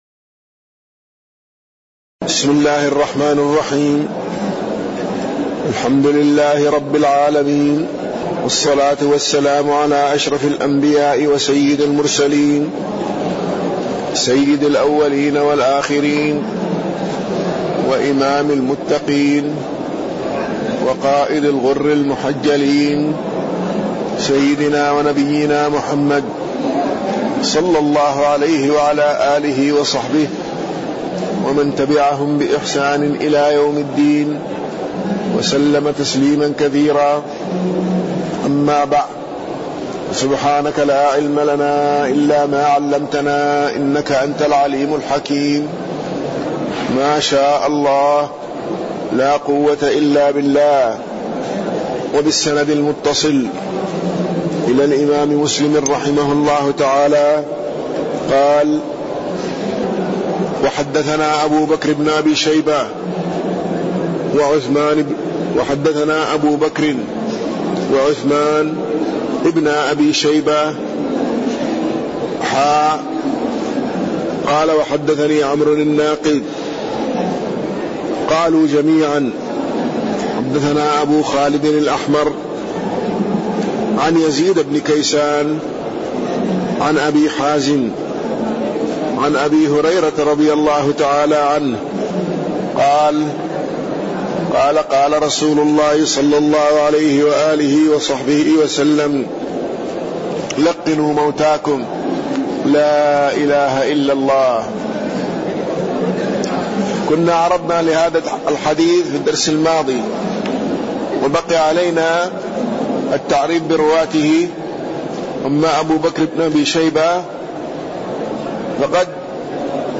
تاريخ النشر ١ صفر ١٤٣٢ هـ المكان: المسجد النبوي الشيخ